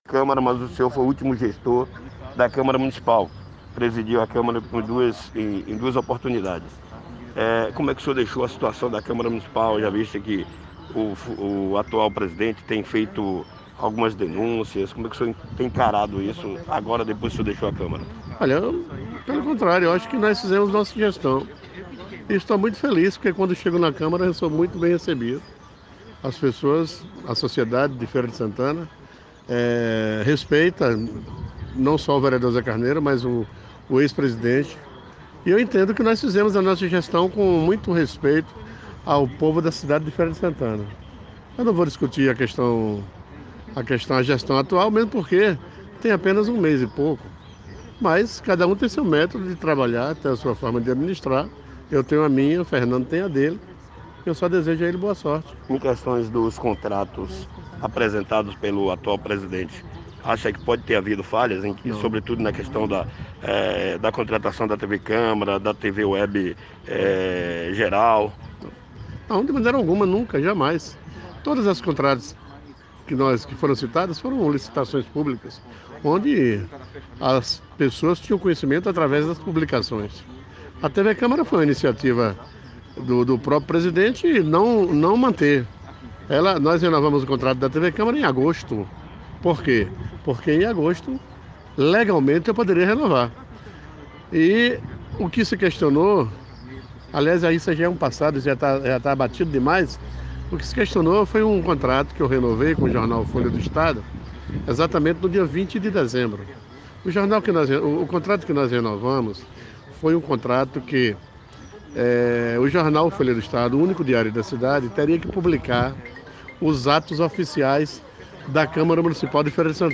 Em entrevista exclusiva ao Programa Rota da Informação na última quinta-feira (12), na rádio Rota News Web, o Secretario de Meio Ambiente de Feira de Santana, José Carneiro Rocha MDB, disse que não usará o poder da Secrearia como escudo  contra as investiduras do presidente da Câmara em ataques a sua gestão na Casa Legislativa.